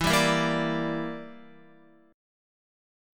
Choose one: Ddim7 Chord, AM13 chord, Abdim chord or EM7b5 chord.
EM7b5 chord